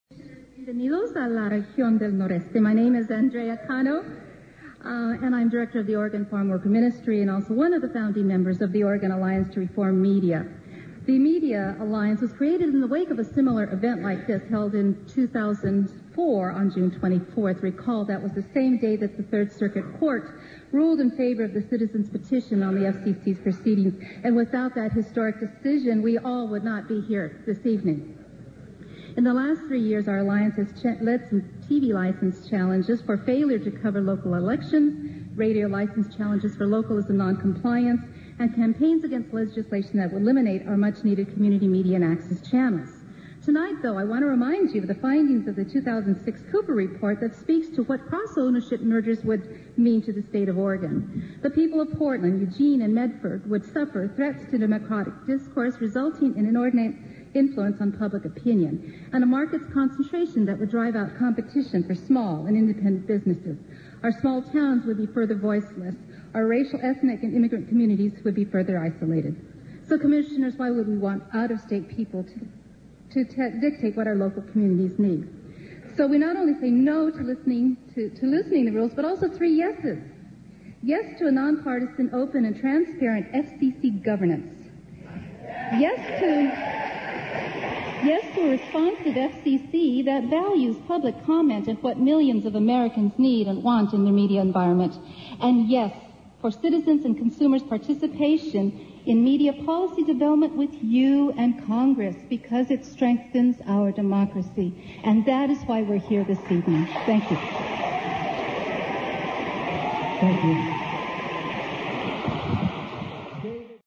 FCC holds public hearings on deregulation : Indybay